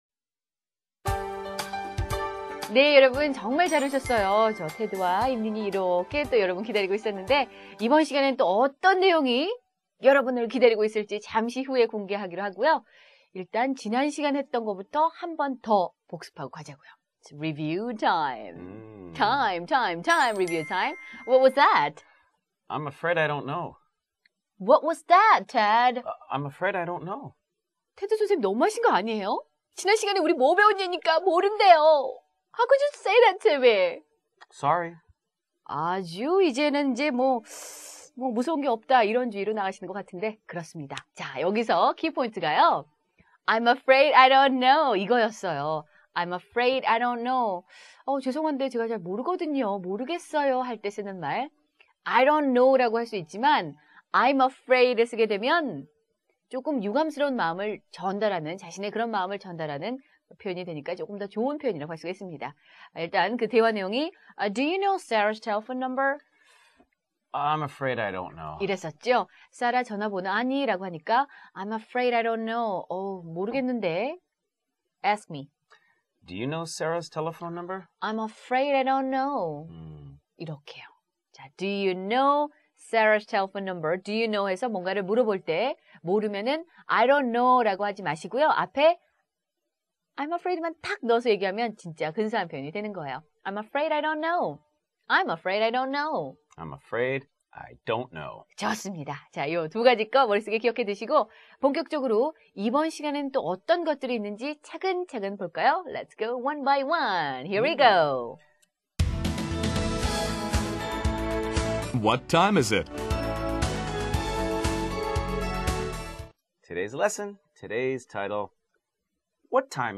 lecture_audio.wma